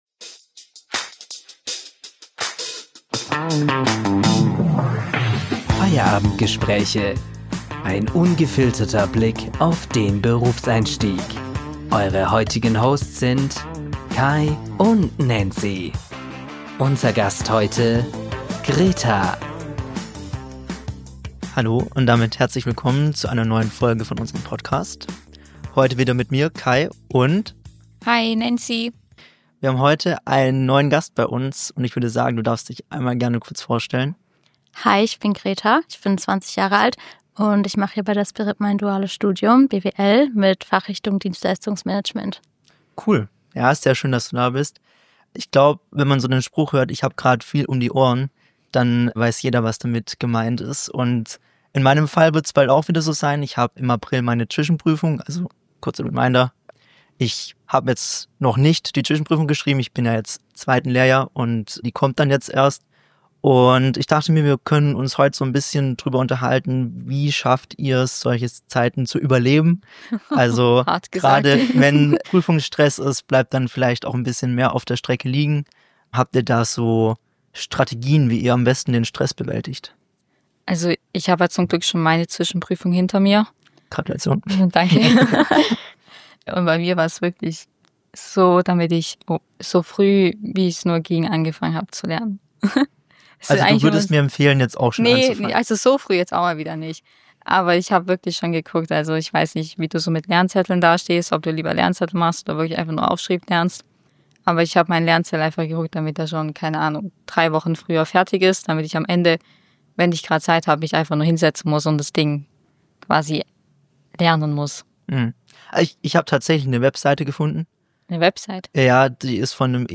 Zwischen Prüfungsstress und Schokocroissants ~ Feierabend Gespräche – Ein ungefilteter Blick auf den Berufseinstieg Podcast